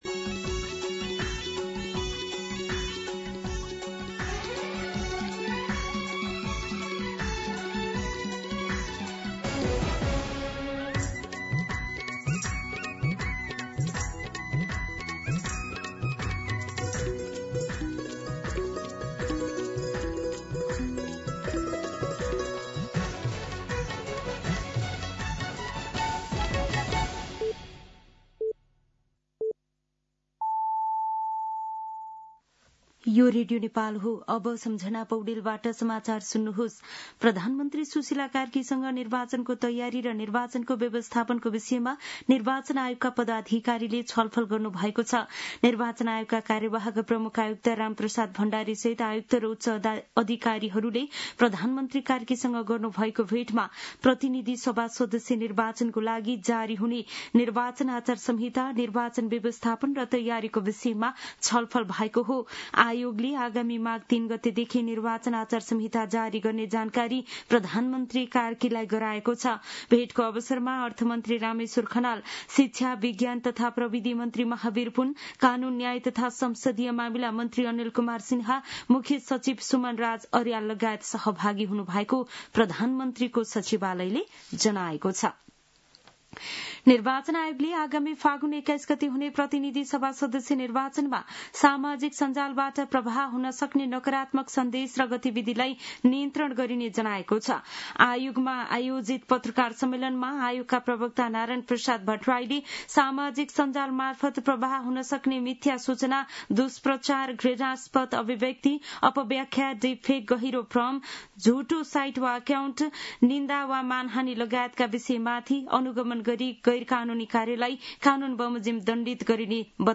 मध्यान्ह १२ बजेको नेपाली समाचार : २६ पुष , २०८२
12-pm-Nepali-News-2.mp3